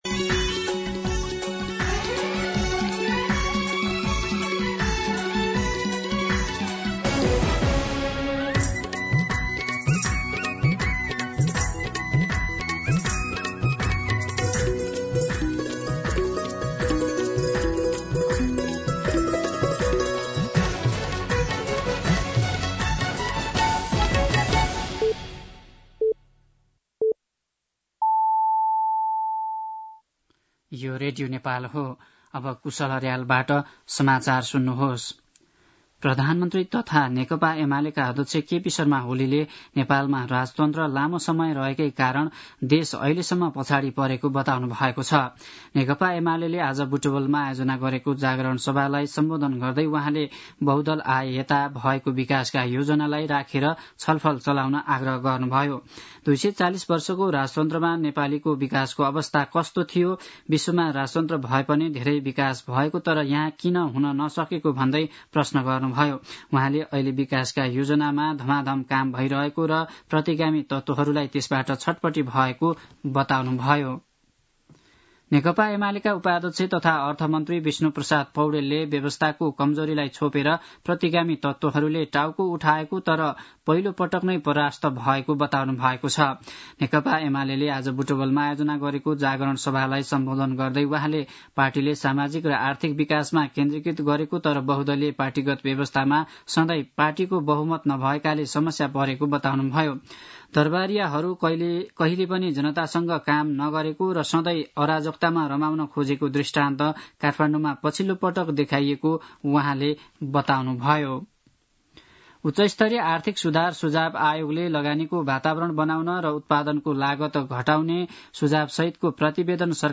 दिउँसो ४ बजेको नेपाली समाचार : २९ चैत , २०८१
4-pm-Nepali-News-2.mp3